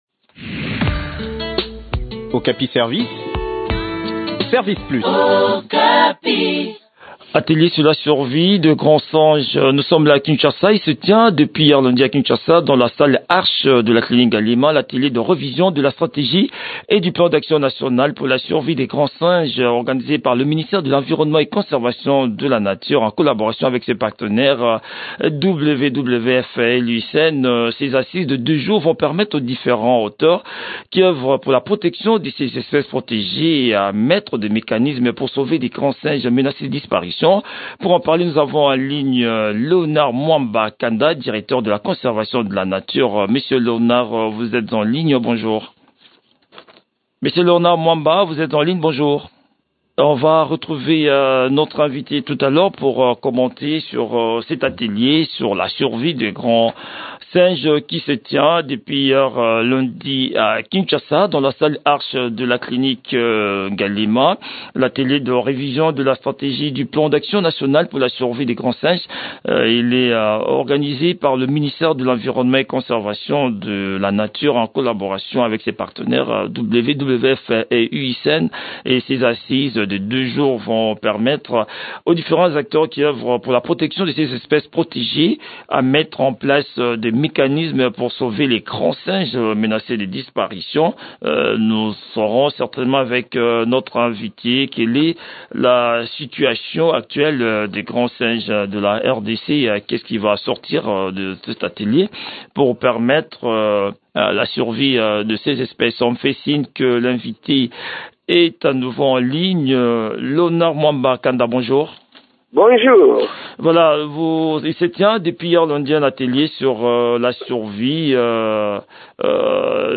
Le but de l’atelier : réfléchir sur les stratégies à mettre en place pour lutter contre le braconnage de ces primates. Le point sur l’organisation de ce séminaire atelier dans cet entretien